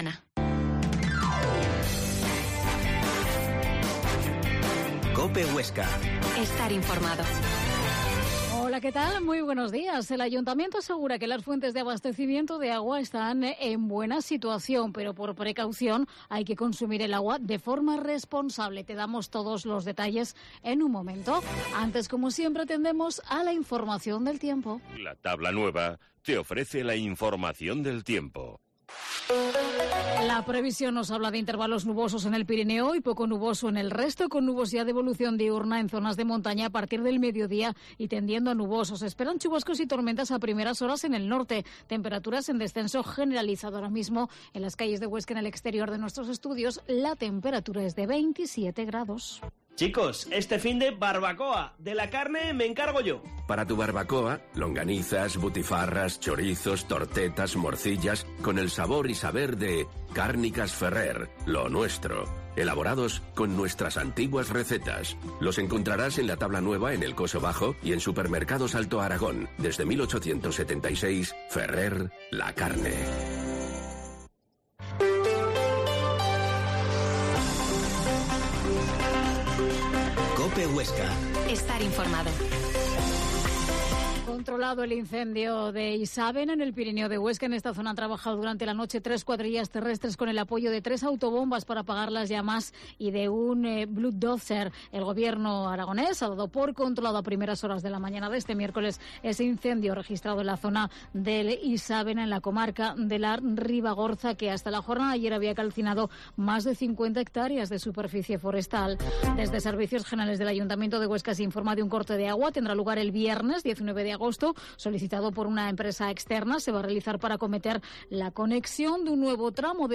Herrera en COPE Huesca 12.50h Entrevista José María Romance, alcalde en funciones